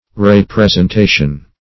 Re-presentation \Re-pres`en*ta"tion\ (r?-prez`?n-t?"sh?n), n.